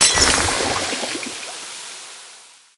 barkeep_bottle_hit_01.ogg